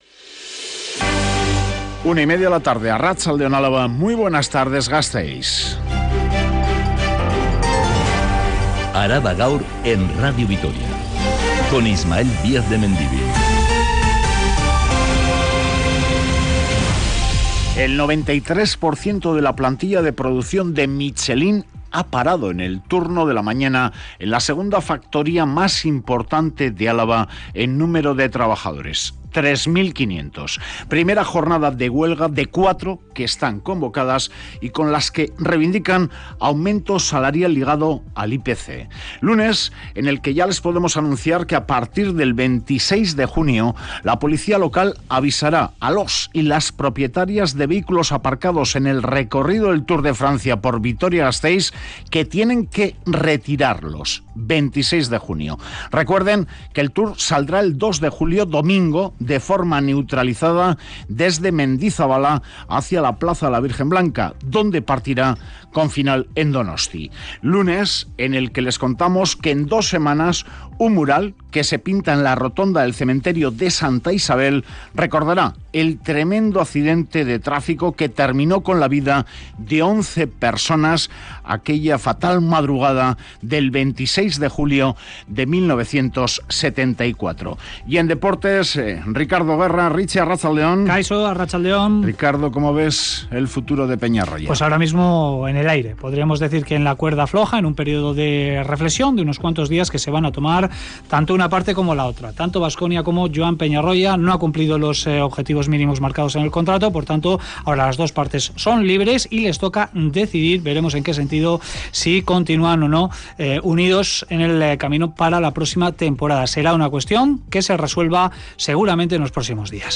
Radio Vitoria ARABA_GAUR_13H Araba Gaur (Mediodía) (05/06/2023) Publicado: 05/06/2023 14:28 (UTC+2) Última actualización: 05/06/2023 14:28 (UTC+2) Toda la información de Álava y del mundo. Este informativo que dedica especial atención a los temas más candentes de la actualidad en el territorio de Álava, detalla todos los acontecimientos que han sido noticia a lo largo de la mañana.